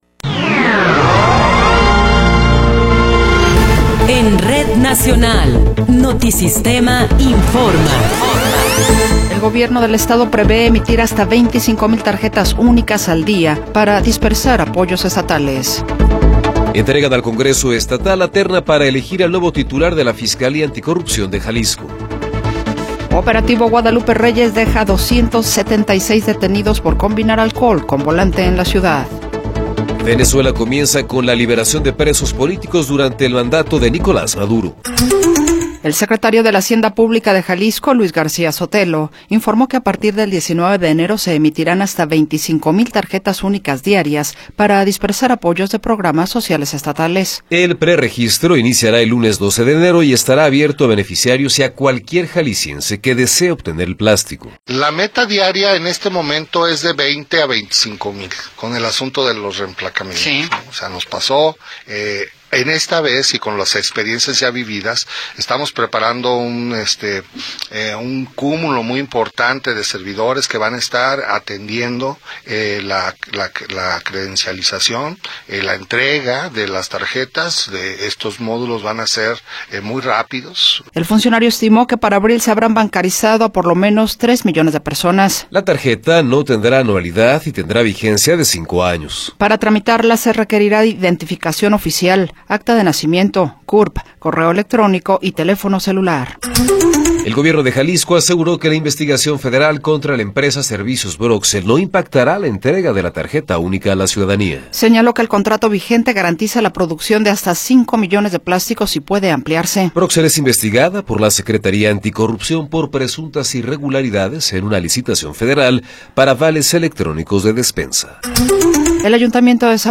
Noticiero 14 hrs. – 8 de Enero de 2026